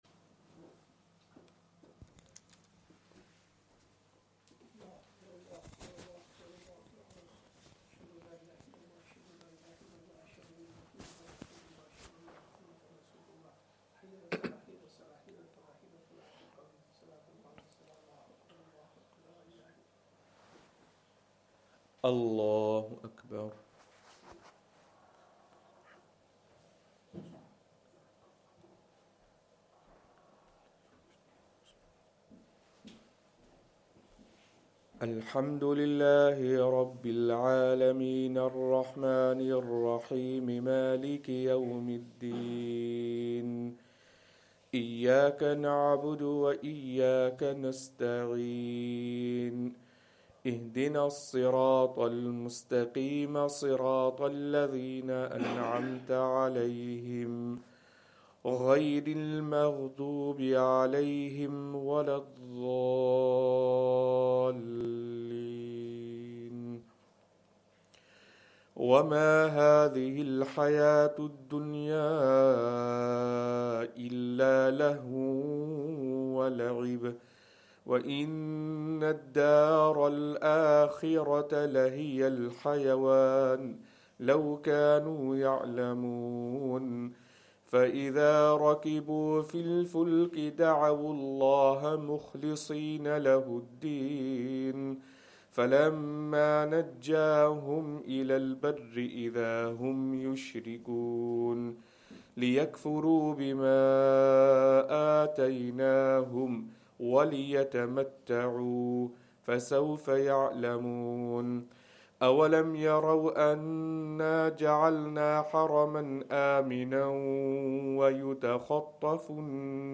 Fajr Surah Ar Room
Madni Masjid, Langside Road, Glasgow